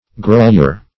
Grolier \Gro"lier`\, n.